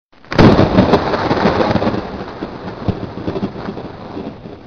thunder-clap_25066.mp3